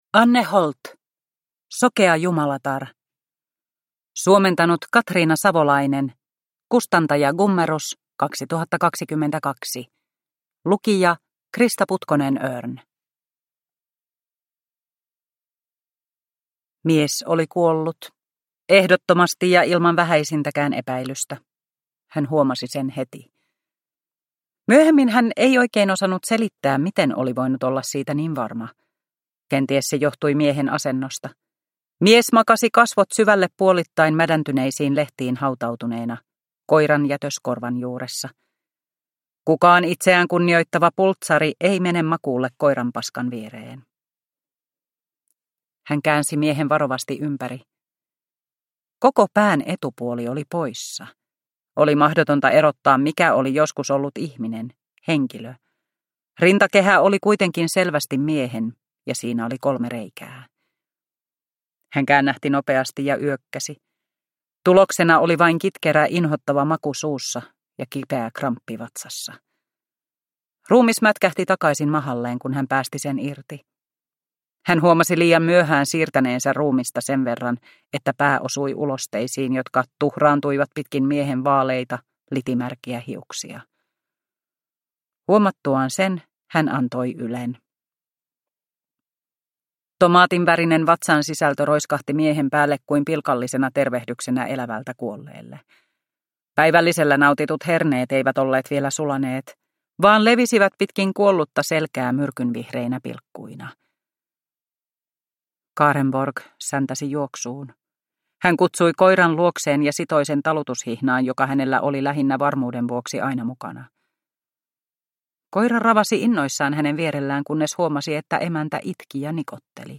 Sokea jumalatar – Ljudbok – Laddas ner